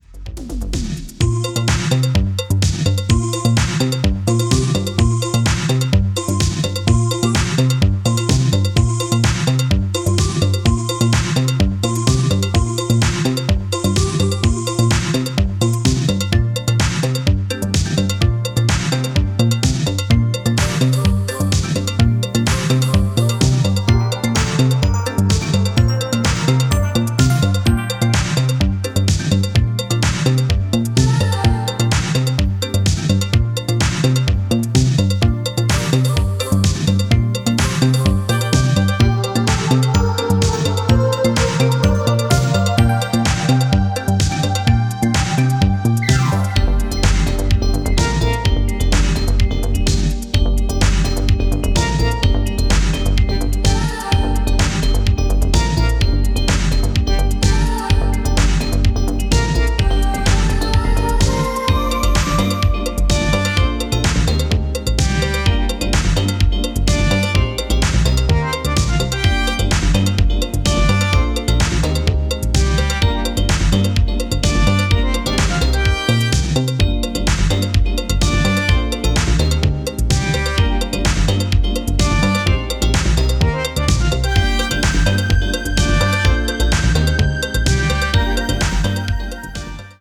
Stylistic, enigmatic, and effortless.
is a smooth melange of glossy soul, jazz, and serene house
hypnotic house and tropical flare
drum machines, synths